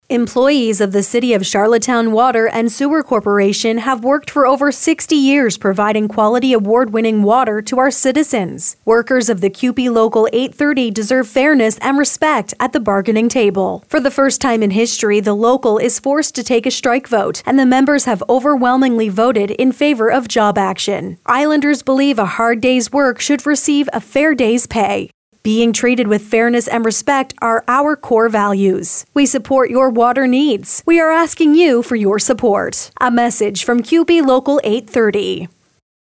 Latest radio ad